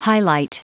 Prononciation du mot highlight en anglais (fichier audio)
Prononciation du mot : highlight